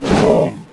Heroes3_-_Royal_Griffin_-_HurtSound.ogg